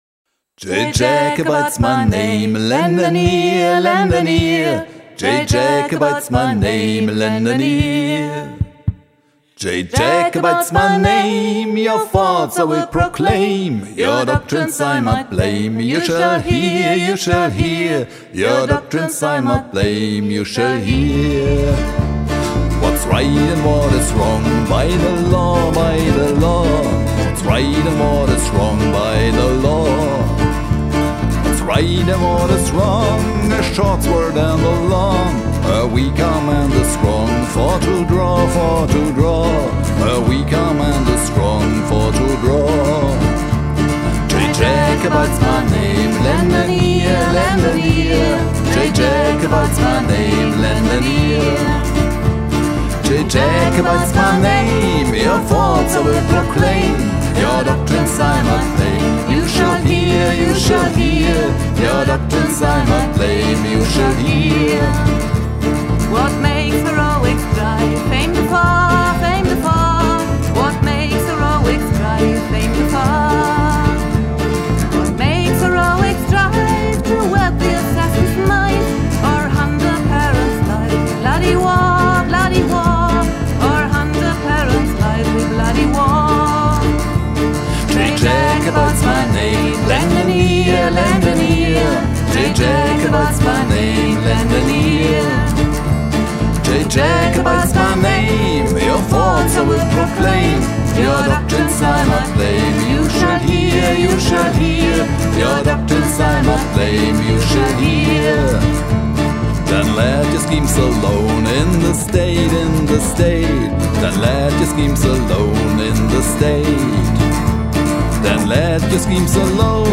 aufgenommen im Oktober 2023 im Tonstudio